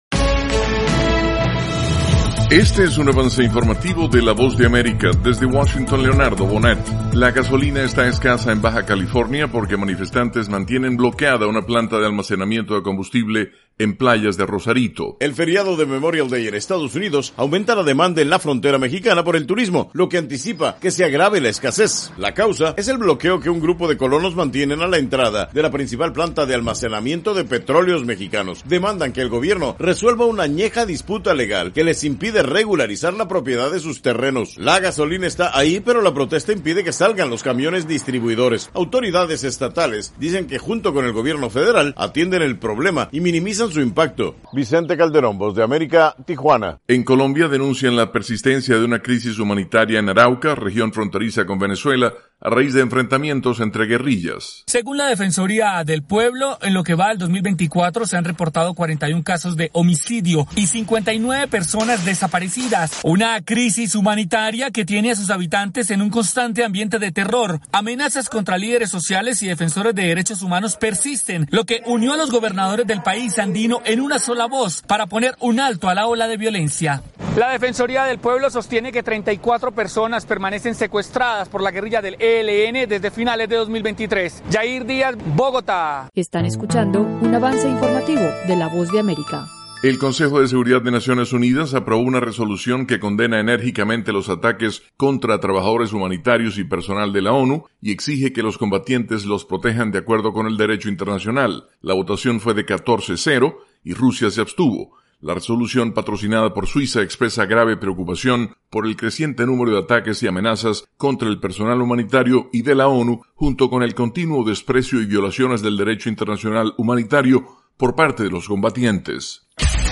El siguiente es un avance informativo presentado por la Voz de América, desde Washington